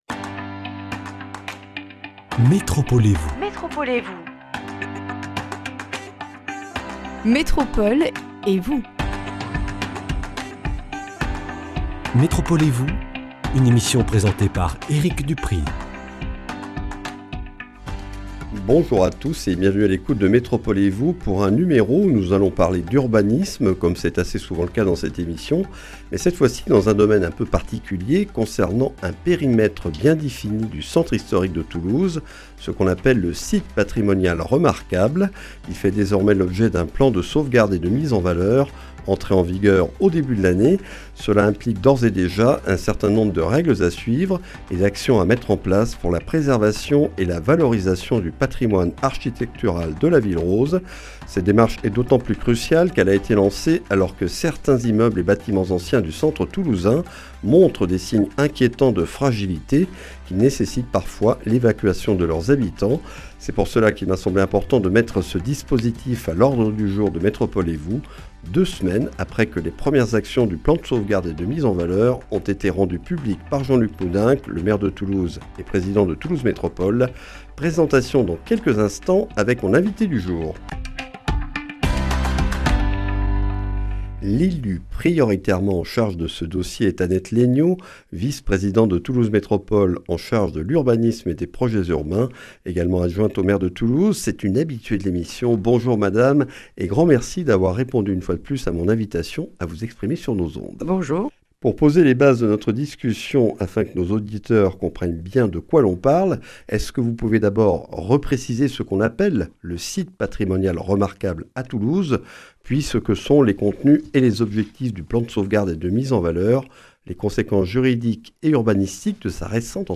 Le PSMV est entré en vigueur en début d’année et ses premières actions viennent d’être lancées. Présentation avec Annette Laigneau, adjointe au maire de Toulouse, vice-présidente de Toulouse Métropole (Urbanisme et Projets urbains).